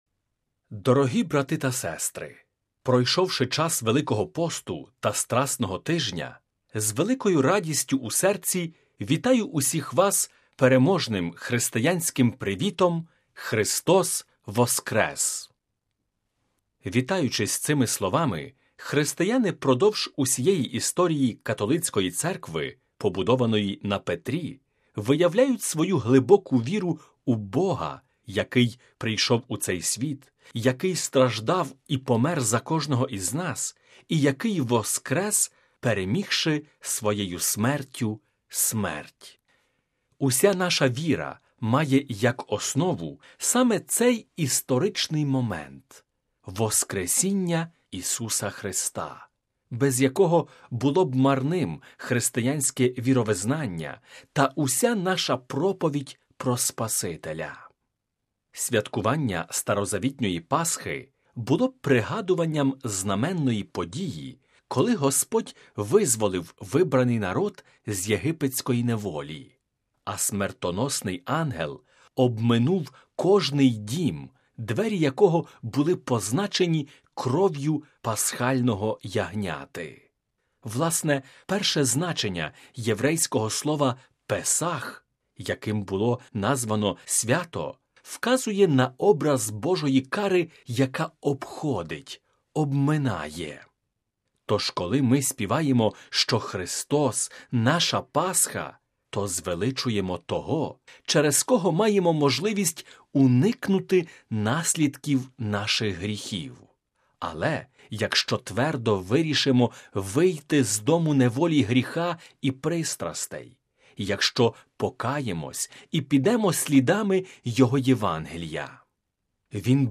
Св. Літургія на Ватиканському Радіо у празник Воскресіння Христового (01.05.2016)
Пропонуємо вашій увазі звукозапис цієї Святої Літургії: Проповідь: